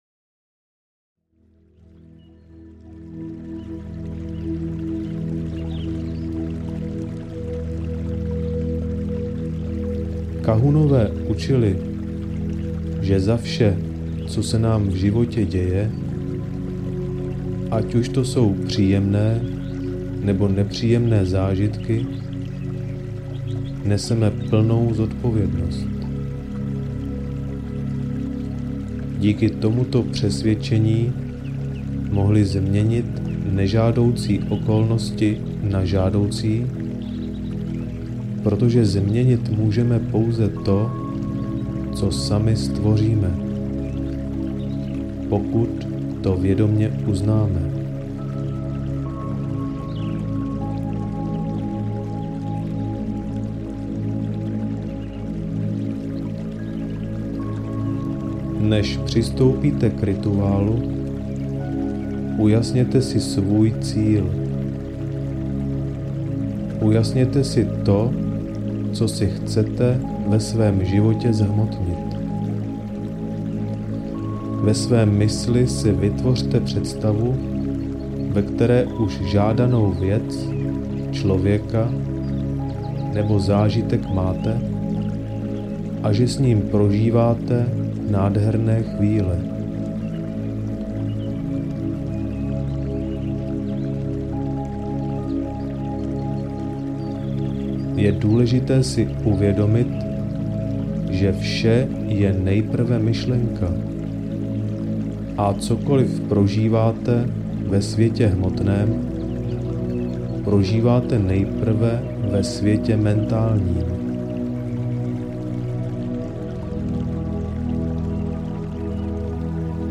Rituál Huna audiokniha
Ukázka z knihy